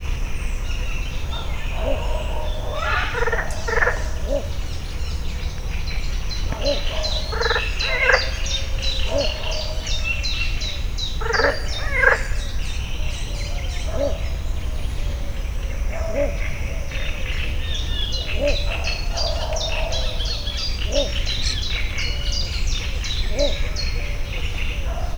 anadasszeliben00.25.WAV